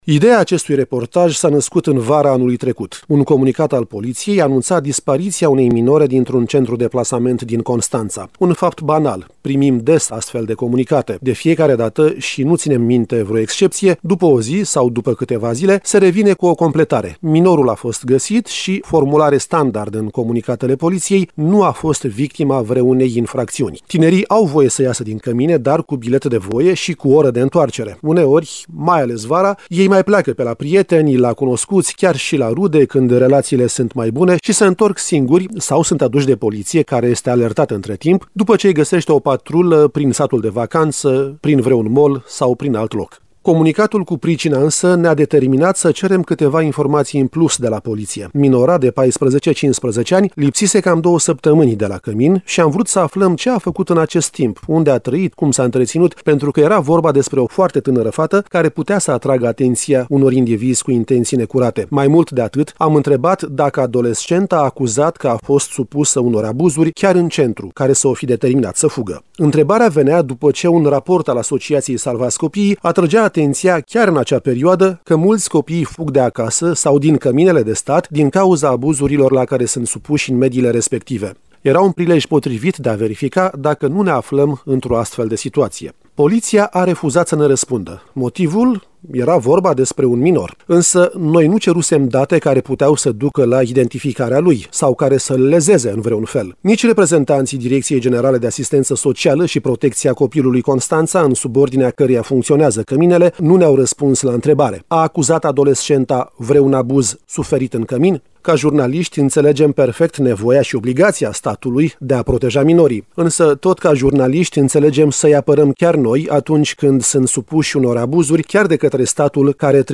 Un reportaj cutremurător despre destine marcate de abandon, sărăcie și violență.